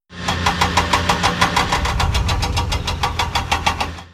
winch.mp3